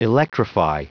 Prononciation du mot electrify en anglais (fichier audio)
Prononciation du mot : electrify